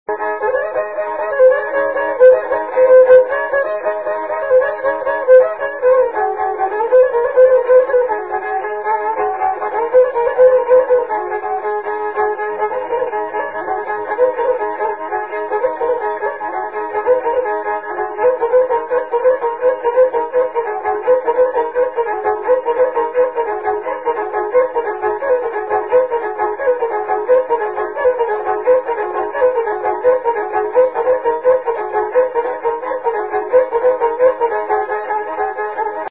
музикална класификация Инструментал
размер Седем шестнадесети
фактура Двугласна
начин на изпълнение Солово изпълнение на гъдулка
битова функция Ръченица
фолклорна област Средногорие
място на записа Дюлево
начин на записване Магнетофонна лента